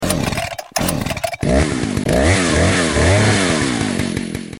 Download Chainsaw sound effect for free.
Chainsaw